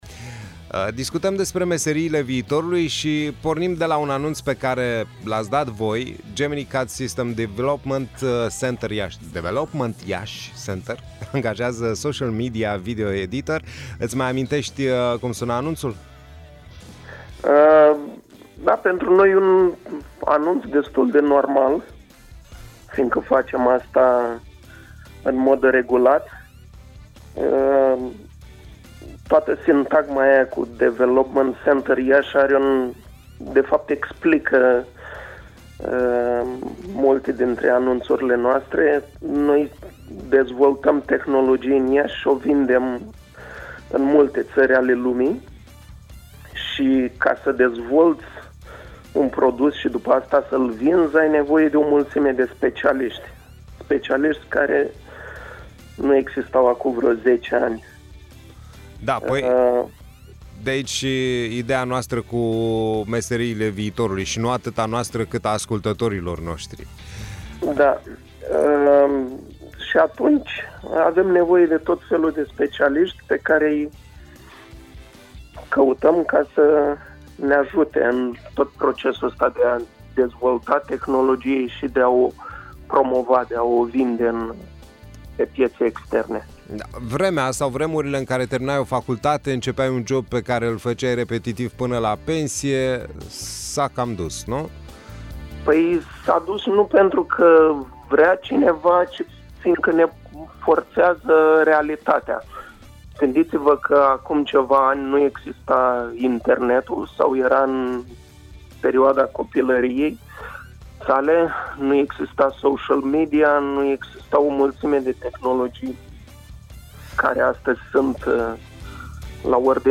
Meserii care pînă acum zece ani păreau desprinse din filme SF sunt acum realitate. O discuție despre IT-ul ieșean